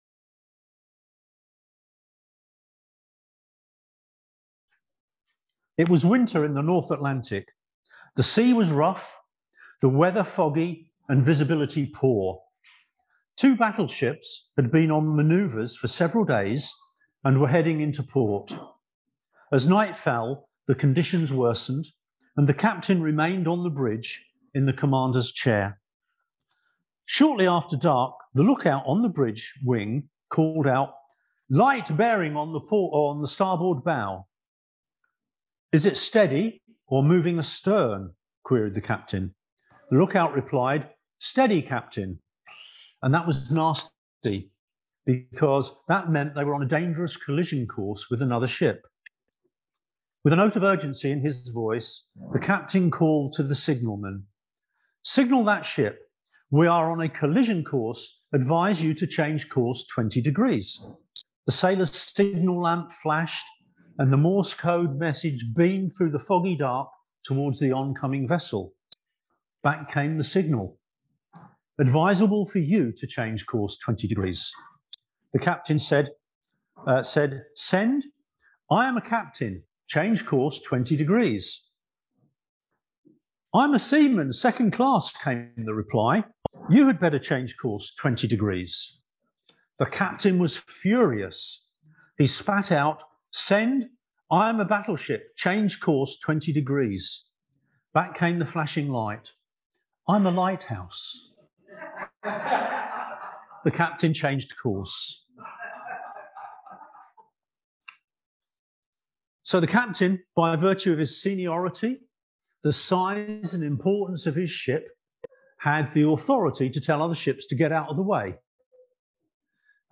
Passage: Matthew 25:31-46, Matthew 9:1-8, John 10:14-18 Service Type: Sunday Service